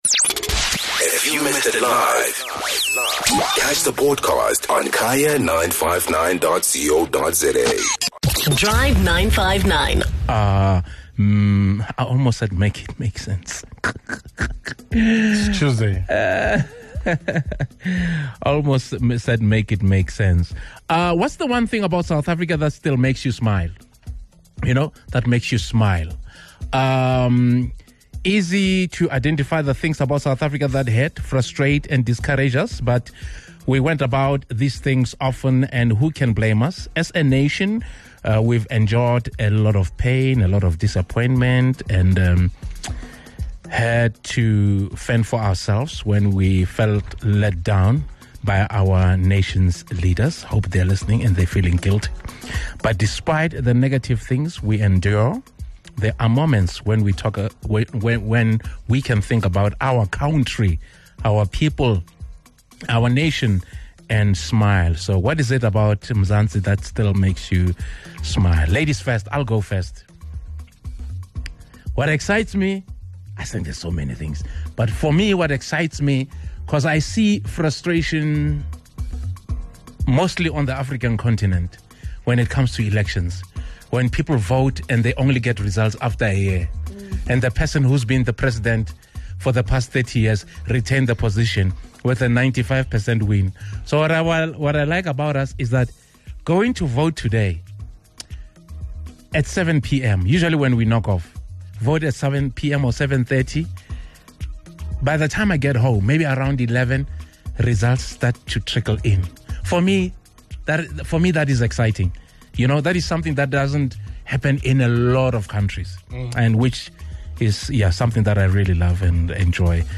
What is it about Mzansi that still makes you smile? Our Drive 959 team had a heartwarming conversation about Mzansi and the little things we love about our country.